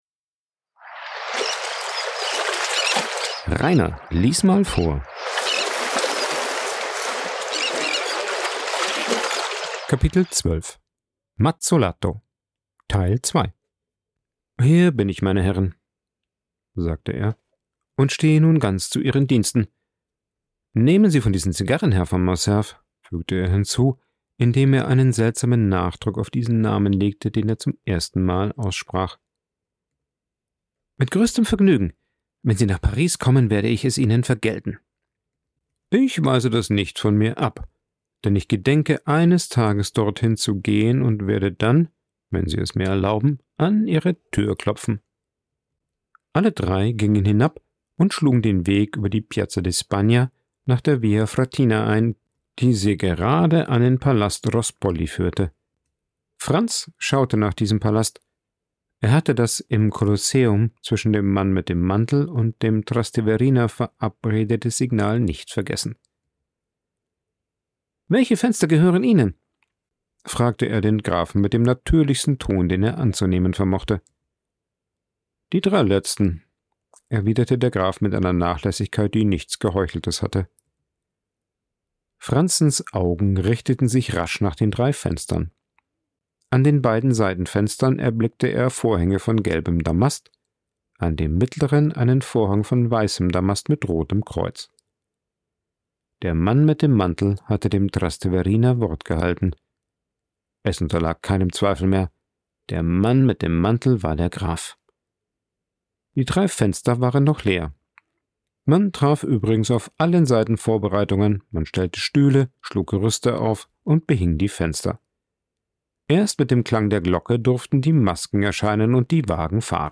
Vorgelesen
aufgenommen und bearbeitet im Coworking Space Rayaworx, Santanyí, Mallorca.